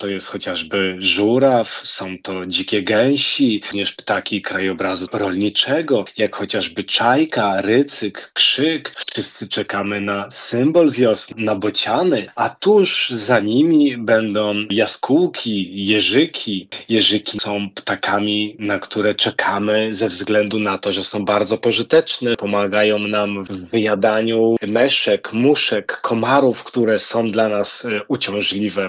O wiosennej migracji ptaków opowiada ornitolog